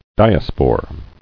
[di·a·spore]